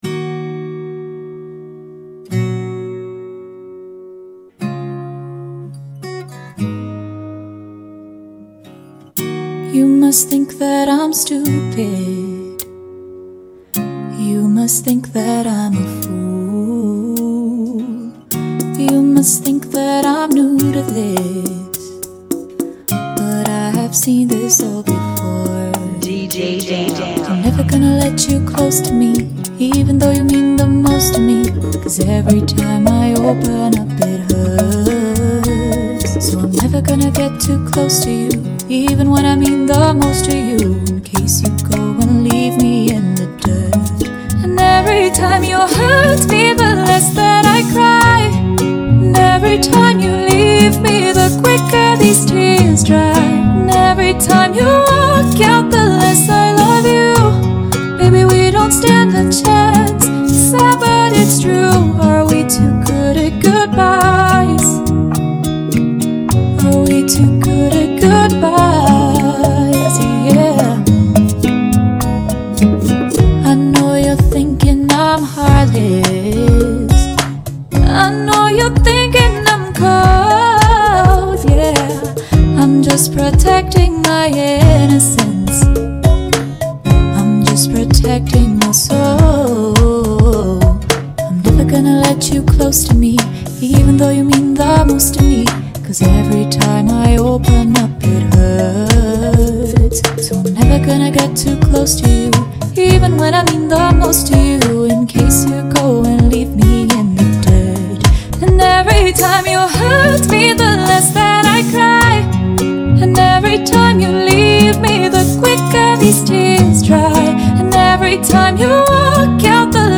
(105 BPM)
Genre: Bachata Remix